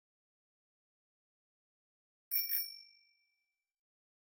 Arquivo:Buzinadebicicleta-audio.ogg